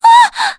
Mediana-Damage_kr_02.wav